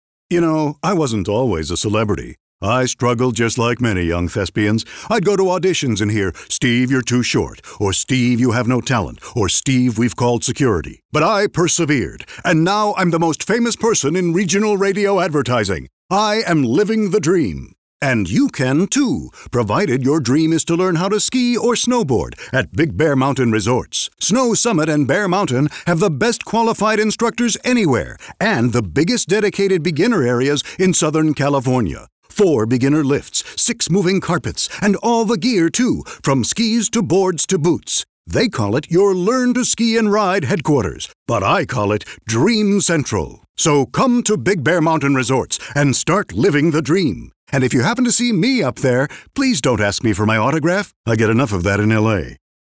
Voice-over